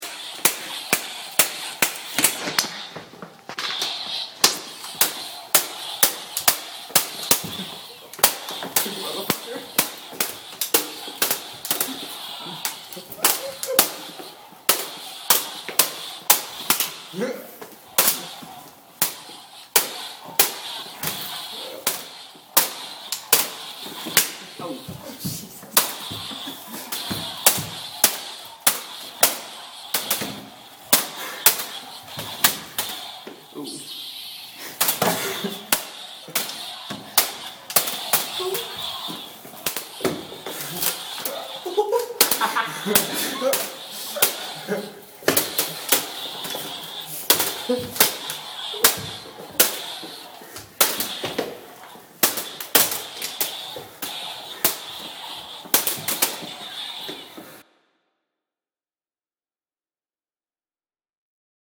Field Recording #3: Epic Lightsaber Battle
2/22/12 10:17PM Emily Lowe Hall
Lightsabers hitting each other and people reacting to said lightsabers.
Lightsaber-battle.mp3